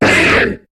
Cri de Ptyranidur dans Pokémon HOME.